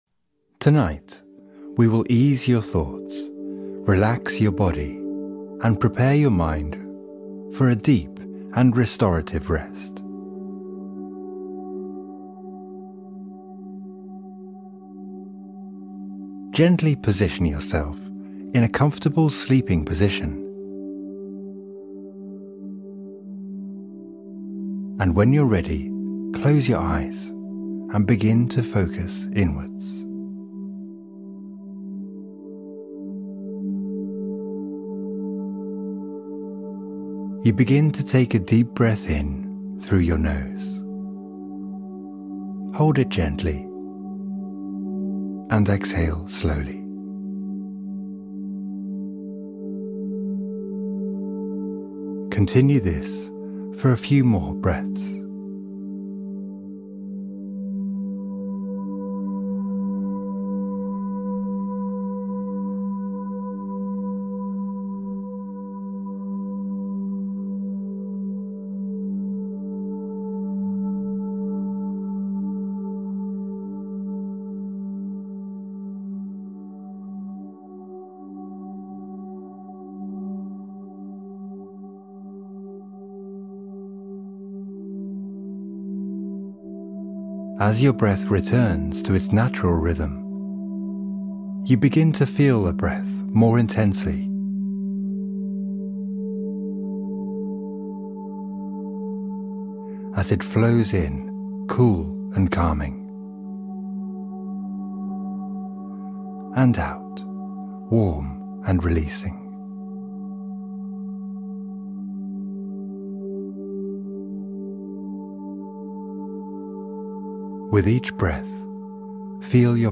Sleep Meditation for the Busy Mind — Guided Relaxation for Restful Sleep